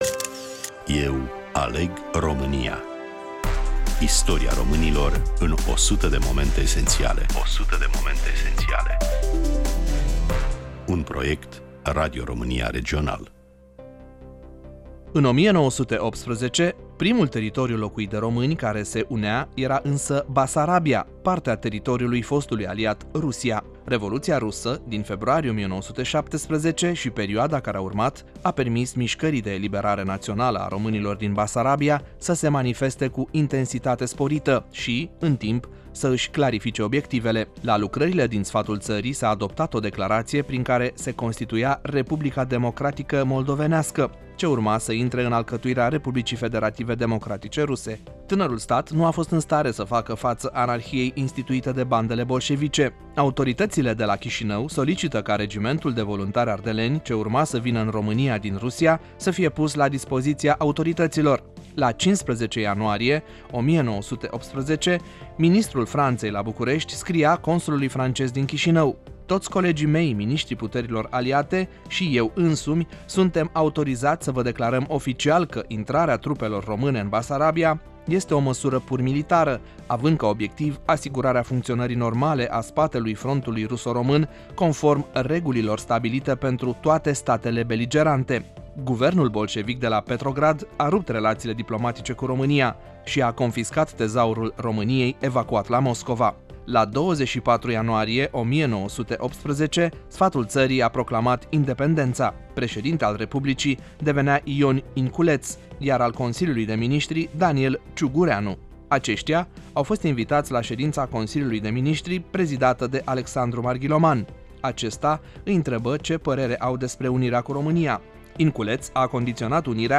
Prezentator / voice over